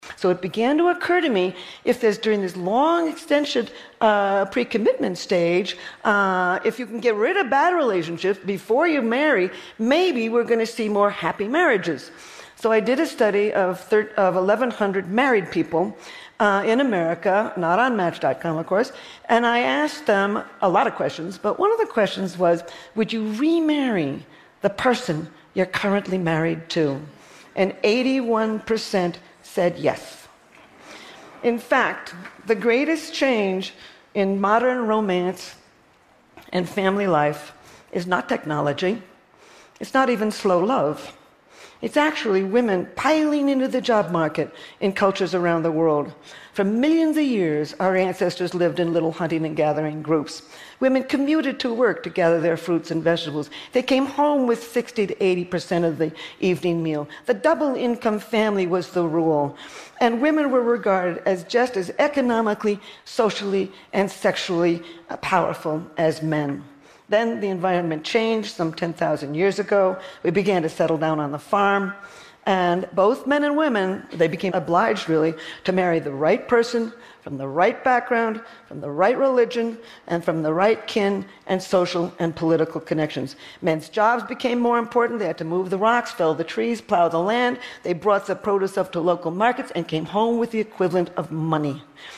TED演讲:科技并没有改变爱 为什么?(7) 听力文件下载—在线英语听力室